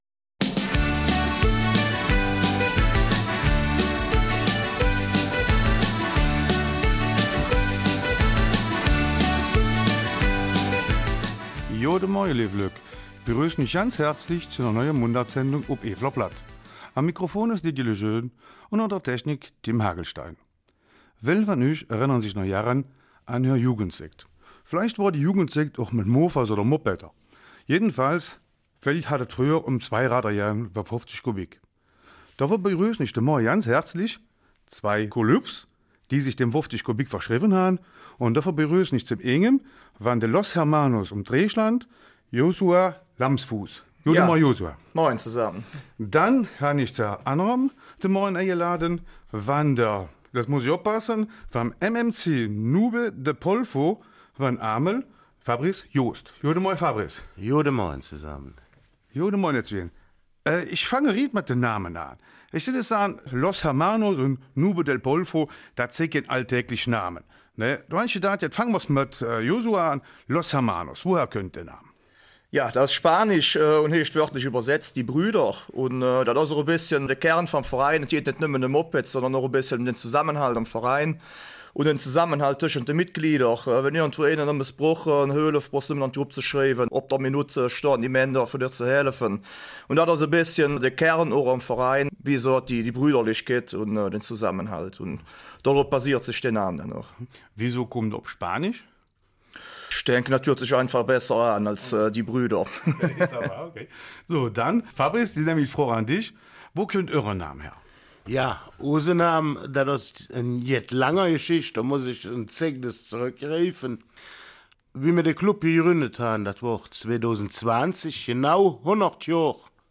Eifeler Mundart: Mofa- und Moped-Clubs in der Eifel